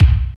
28.02 KICK.wav